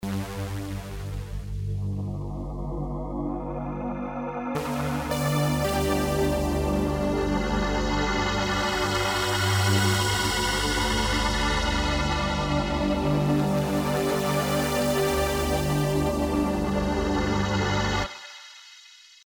Sound per Zufallsgenerator
Diese drei Sounds hat Icarus ohne weiteres Zutun gewürfelt: